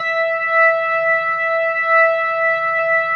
B3LESLIE E 6.wav